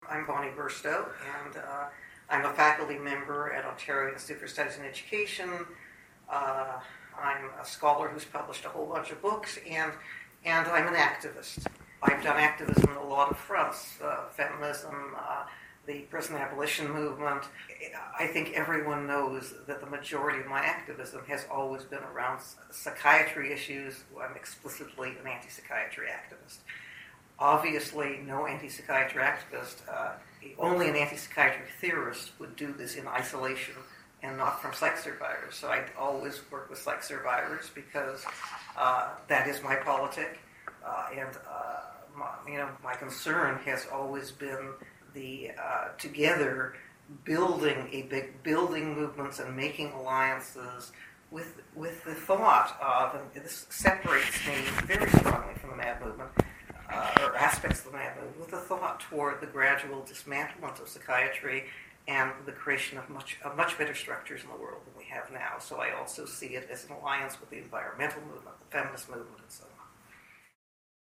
at the time of their oral history interview.